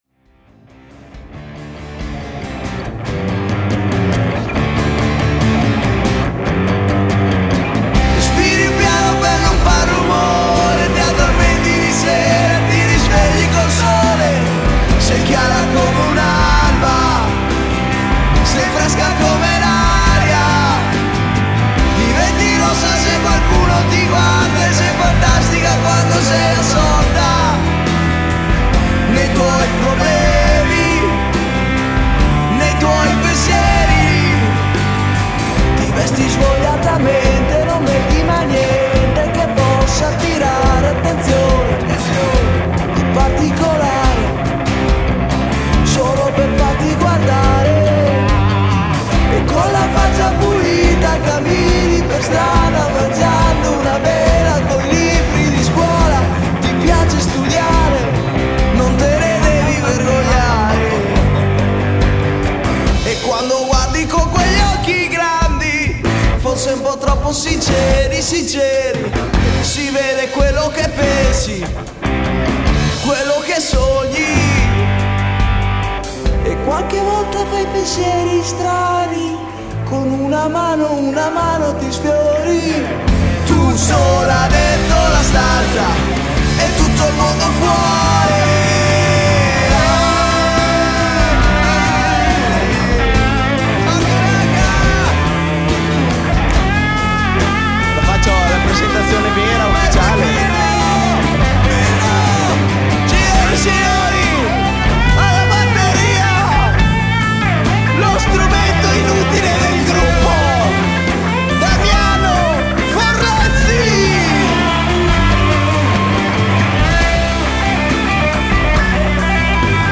Voce, Chitarra Acustica
Voce, Basso
Chitarre
Batteria
Cover pop-rock